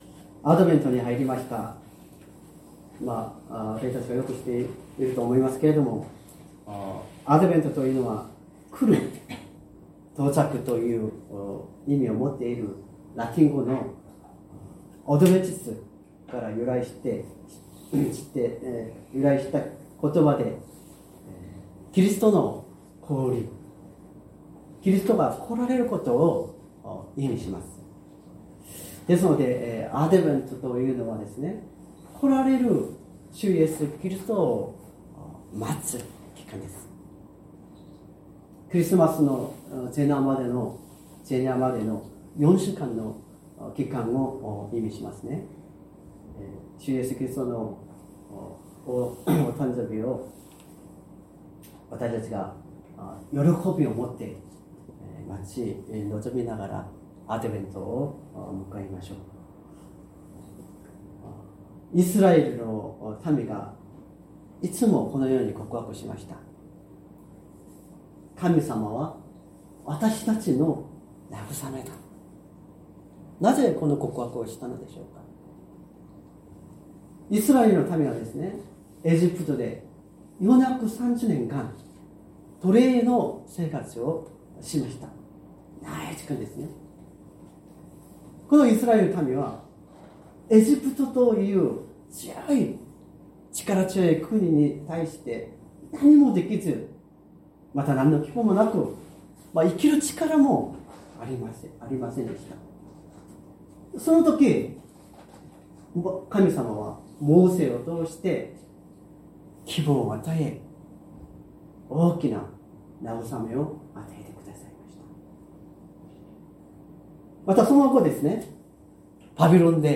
説教アーカイブ 2024年12月01日朝の礼拝「私の民を慰めよ」
音声ファイル 礼拝説教を録音した音声ファイルを公開しています。